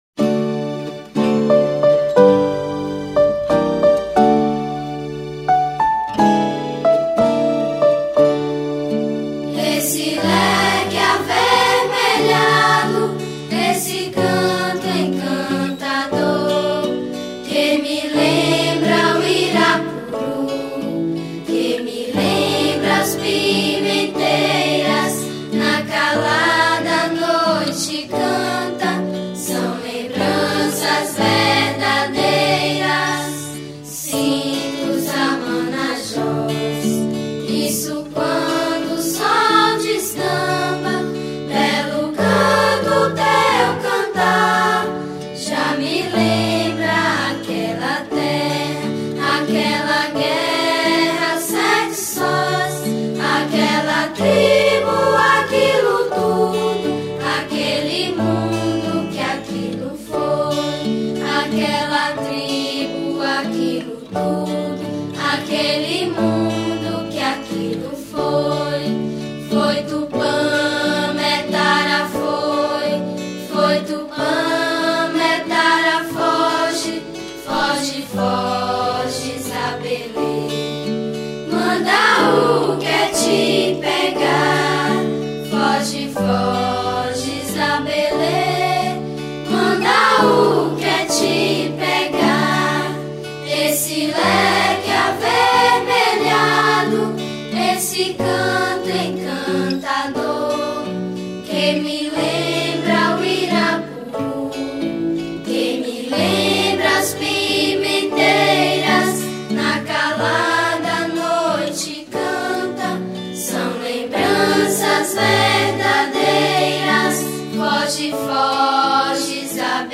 712   02:52:00   Faixa:     Folclore Piauiense